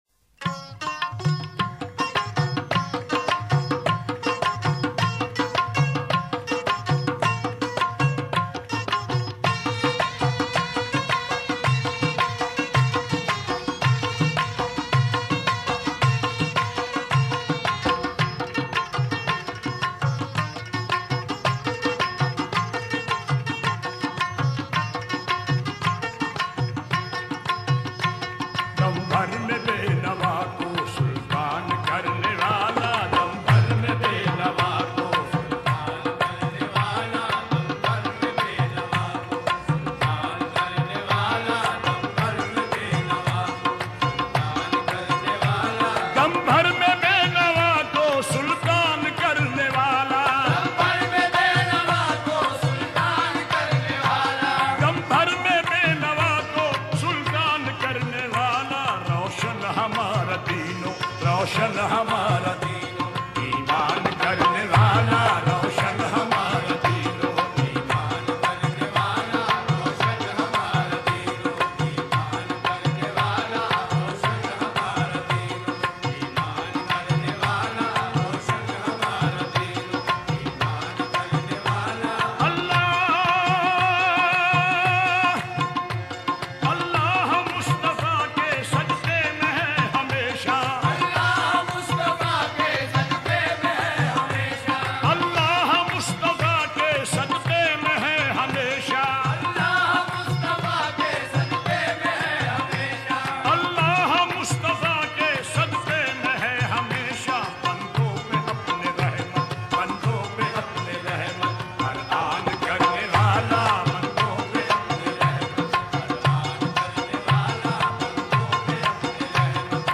Download MP3 Worlds Largest Collection of Qawwali